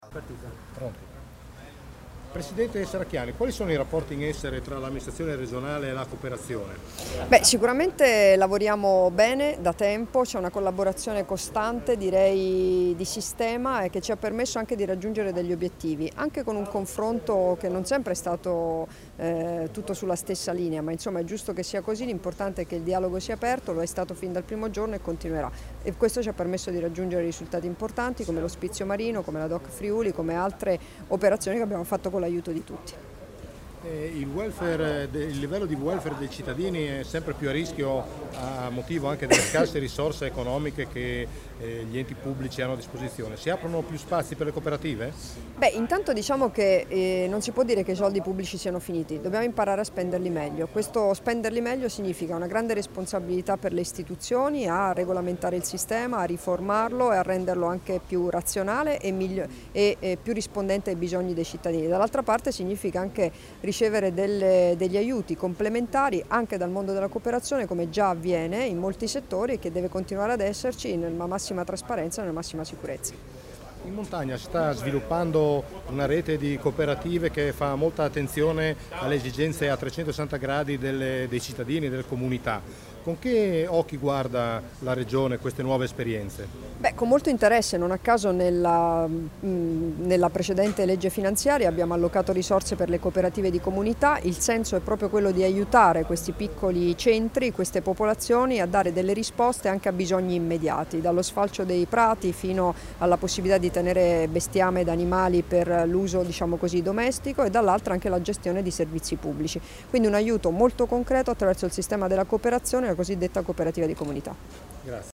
Dichiarazioni di Debora Serracchiani (Formato MP3) [1856KB]
a margine del convegno "Un nuovo paradigma per le politiche sociali. Politiche attive del lavoro, welfare aziendale e servizi di prossimità", rilasciate a Udine l'11 maggio 2017